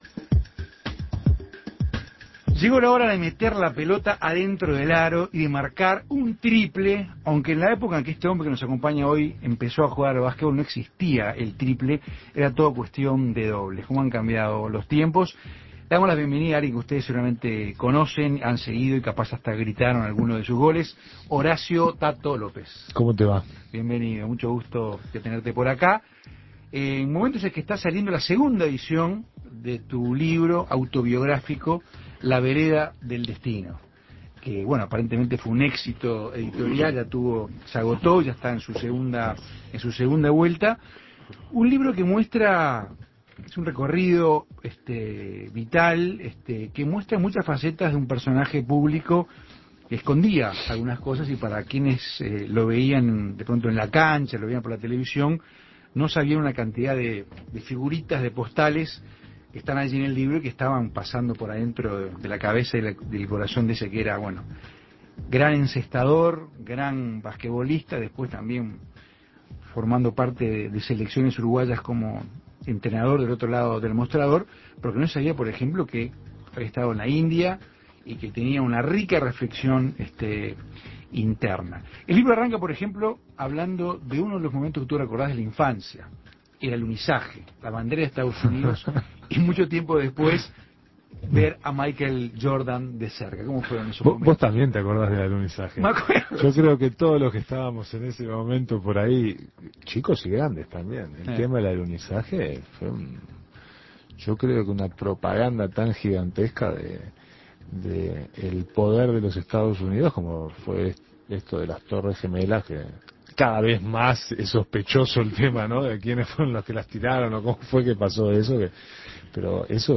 Escuche la entrevista con "Tato" López